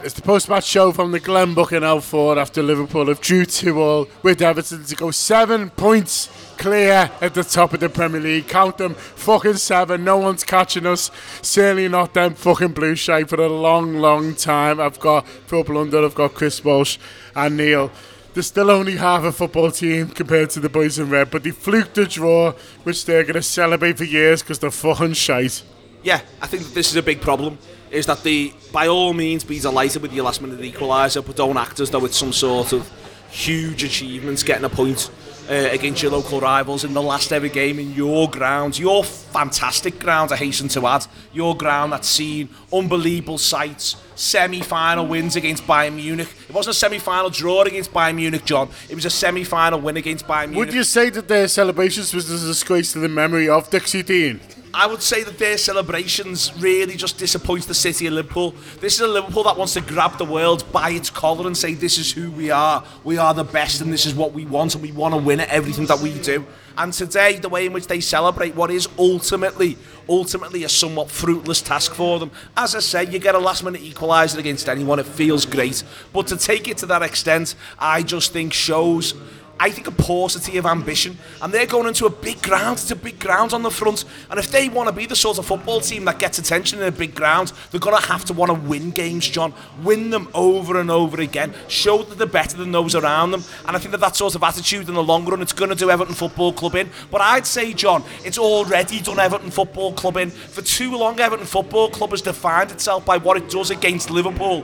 The Anfield Wrap’s post-match reaction podcast after Everton 2 Liverpool 2 at Goodison Park.